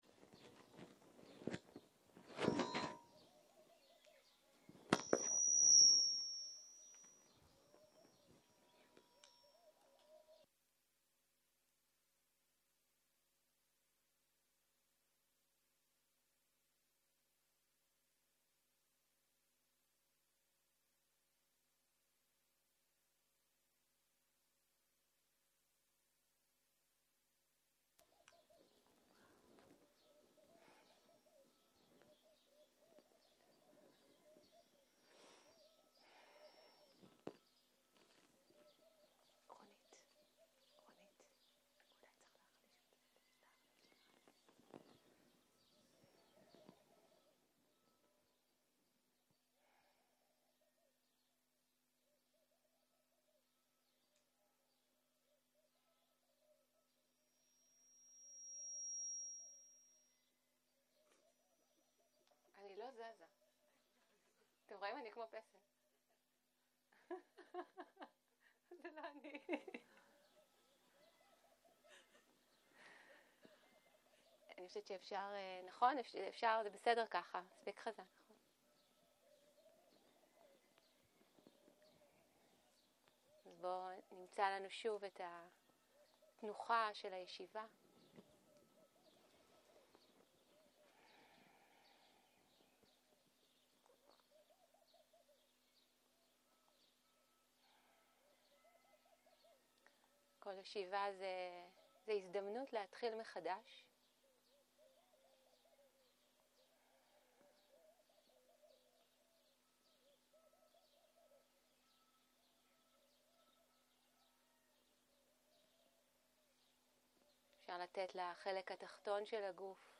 בוקר - הנחיות מדיטציה - סקרנות ואורחים
שיחת הנחיות למדיטציה